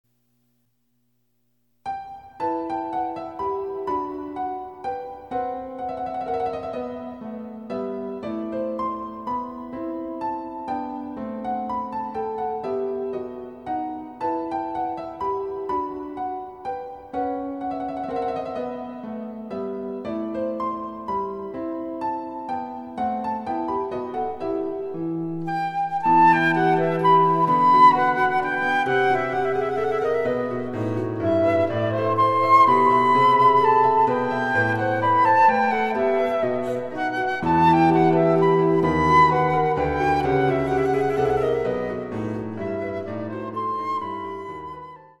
伴奏つきでフルートが演奏できる！
★フルート用の名曲をピアノ伴奏つきで演奏できる、「ピアノ伴奏ＣＤつき楽譜」です。
第１楽章
(1)各楽章につきモダンピッチ(A=442Hz)の伴奏